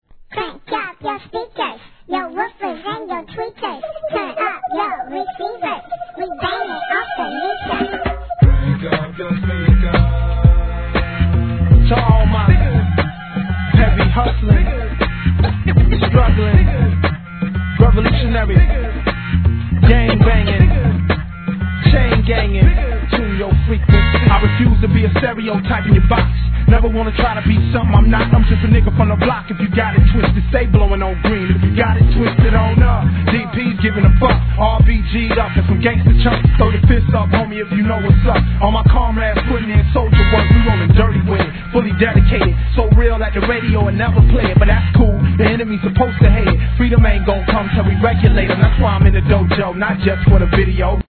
HIP HOP/R&B
オリエンタルなメロが乗った印象的な2003年作